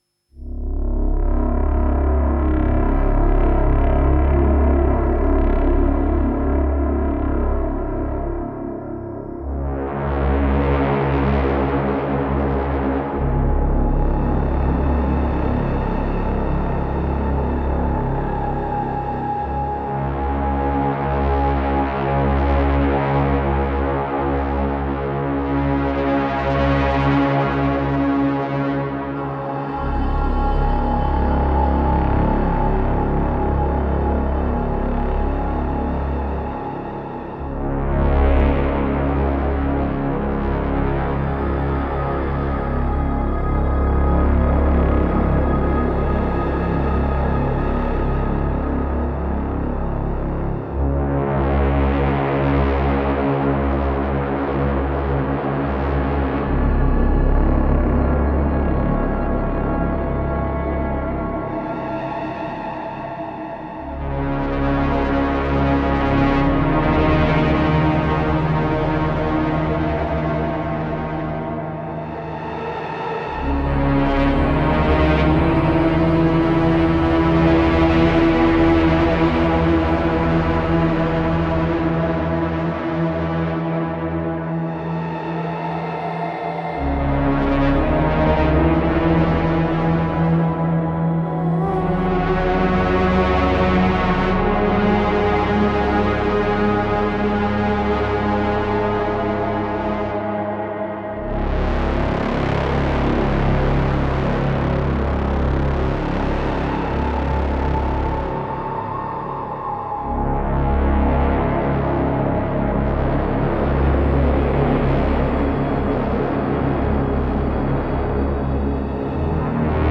Tempo 120 BPM